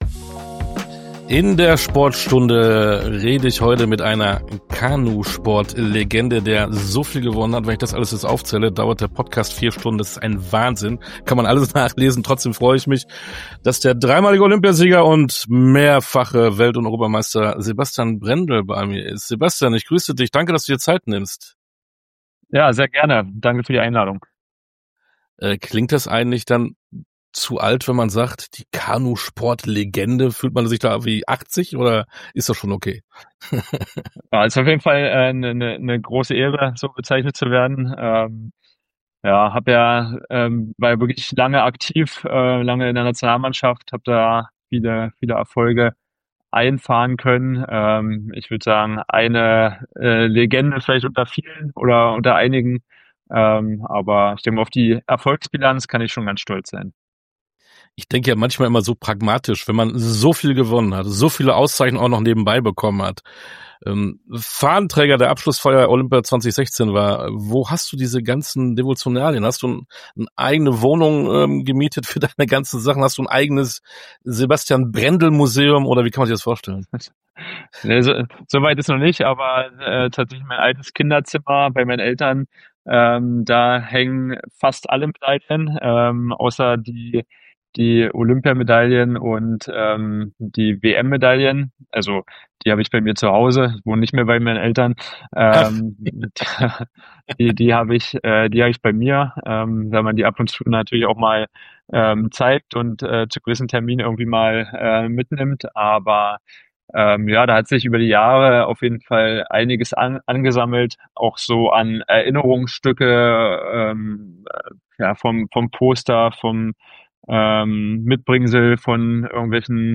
Ein Gespräch voller Leidenschaft, Erfahrung, Humor – und einer riesigen Portion Sportgeschichte.
Interview_Sebastian_Brendel-_Kanusport_-_dreifacher_Olympiasieger.mp3